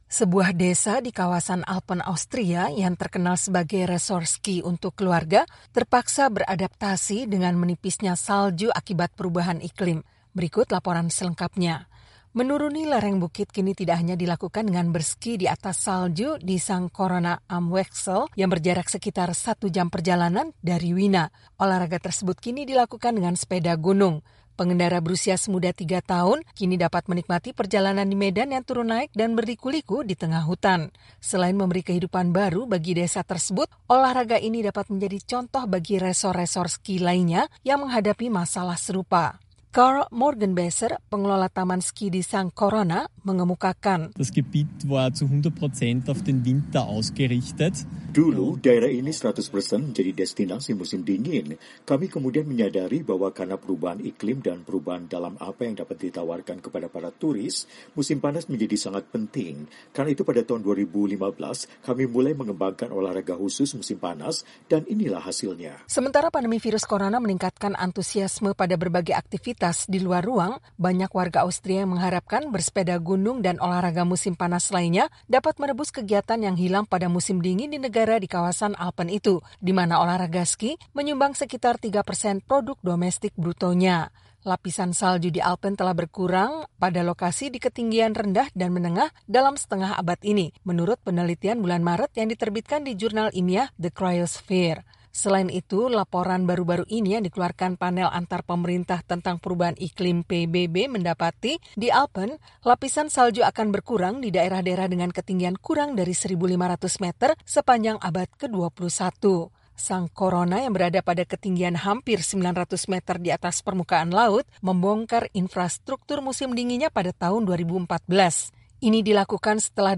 Sebuah desa di kawasan Alpen Austria yang terkenal sebagai resor ski untuk keluarga, terpaksa beradaptasi dengan menipisnya salju akibat perubahan iklim. Berikut laporan selengkapnya.